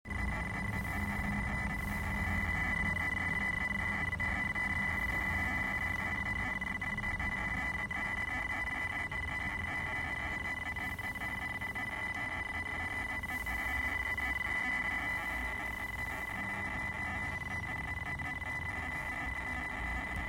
Zwei Fragen: Frage1) Ich vernehme ein mittel-leises Geräusch (ca. 2 kHz, wie ein Faxgerät) unter der Sozia.Sitzbank, also hinter dem Endtopf ( roter Kreis ), wenn die Zündung an ist. Eine Sound-Datei habe ich in der Garage aufgenommen.
(Wenn der Motor gestartet wird und läuft, kann man es nicht mehr detektieren).